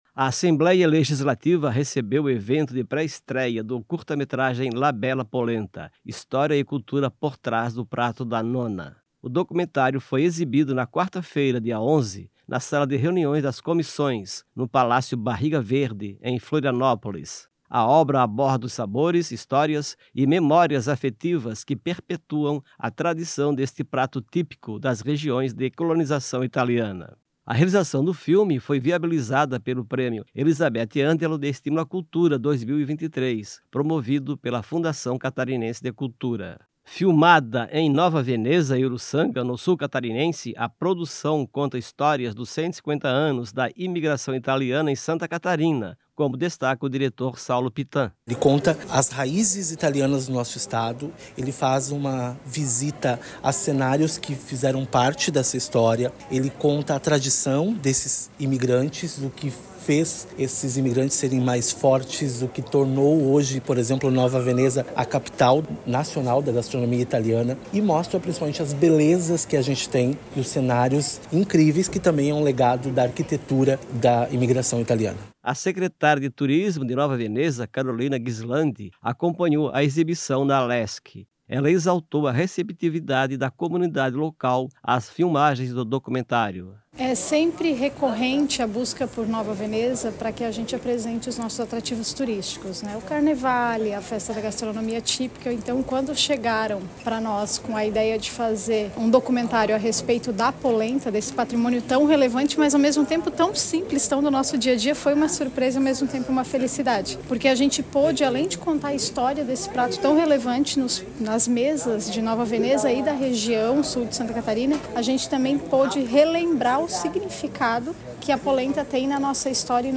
Enrevistas com:
- Deputado Thiago Zilli (MDB);
- Carolina Ghislandi, secretária de Turismo de Nova Veneza;